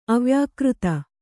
♪ avyākřta